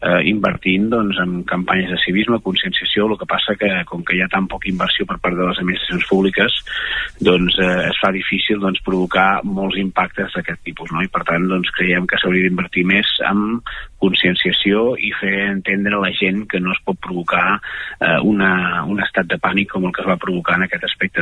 En una entrevista a Ràdio Capital